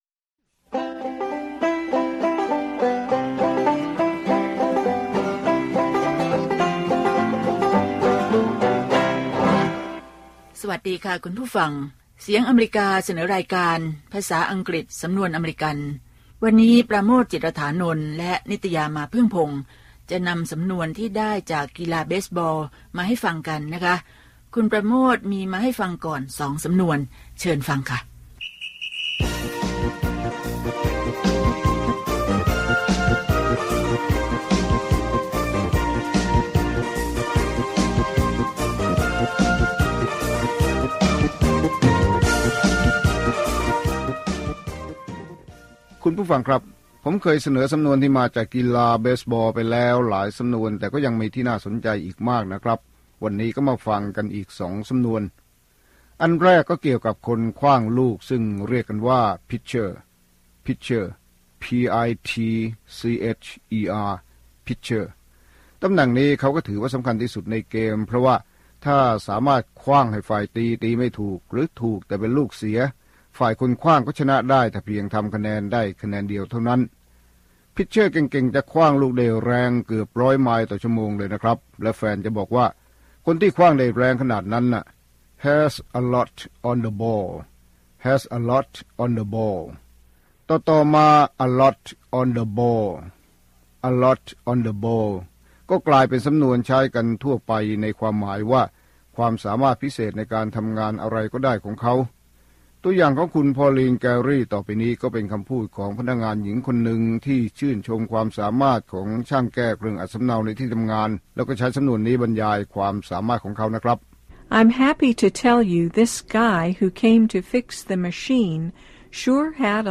ภาษาอังกฤษสำนวนอเมริกัน สอนภาษาอังกฤษด้วยสำนวนที่คนอเมริกันใช้ มีตัวอย่างการใช้ และการออกเสียงจากผู้ใช้ภาษาโดยตรง